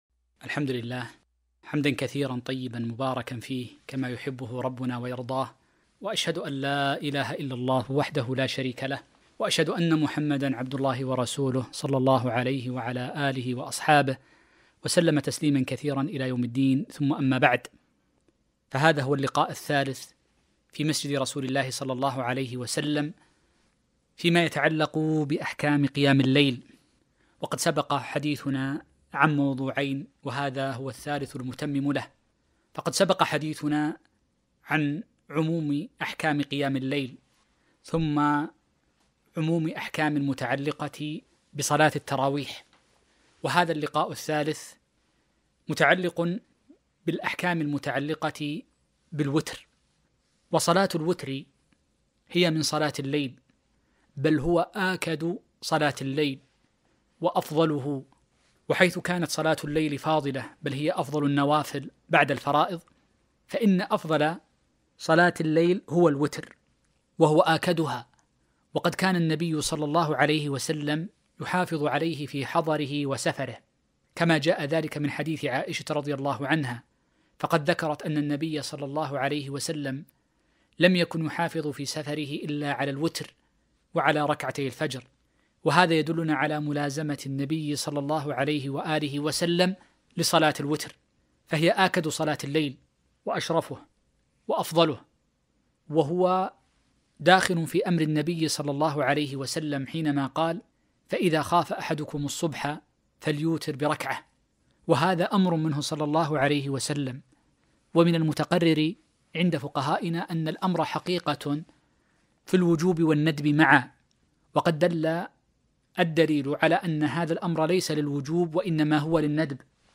محاضرة - فضل قيام رمضان ومشروعية صلاة